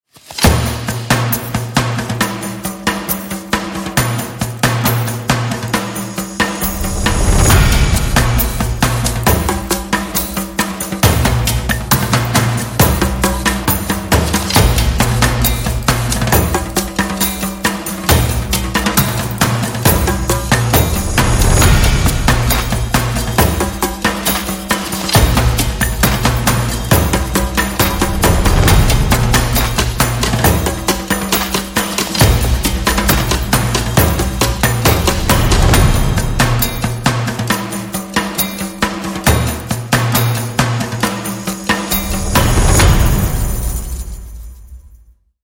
Percussion World